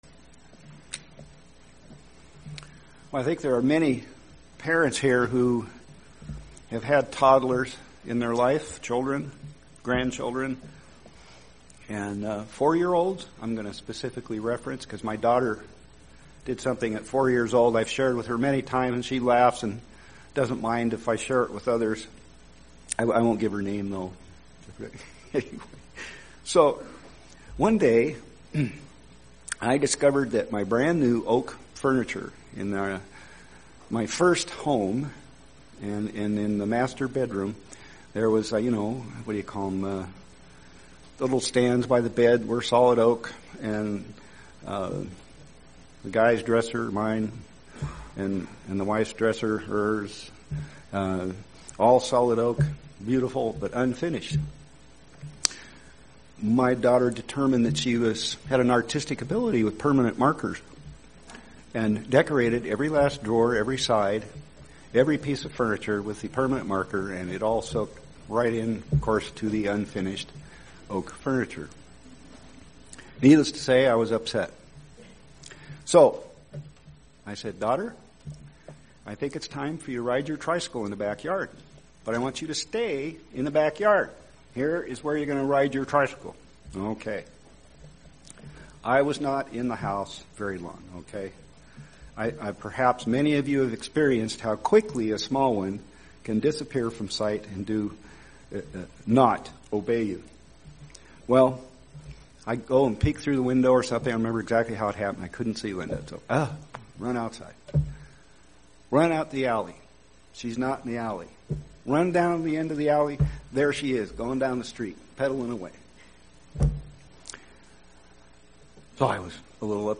Listen to this sermon to find out where the very best place to dwell is!